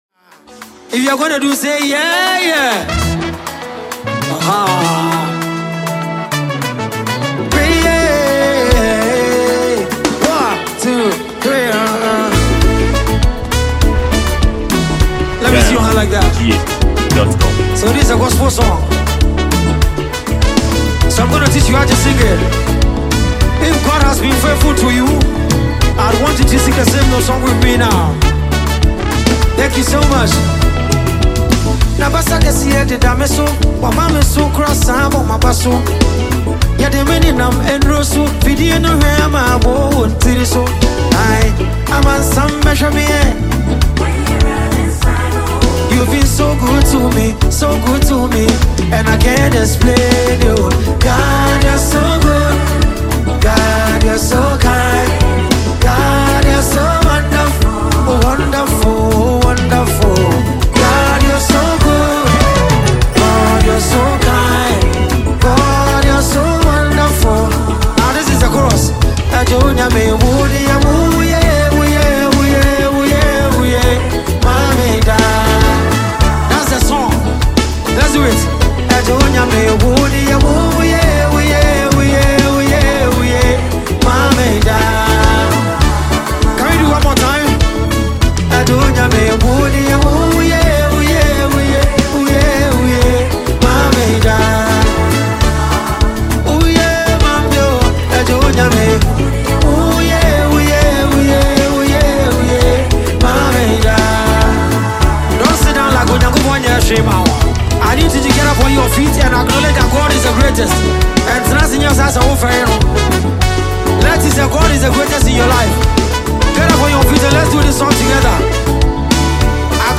Ghana Music, Gospel
Ghanaian highlife singer and songwriter
a gospel tune to believers.